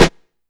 Snare (65).wav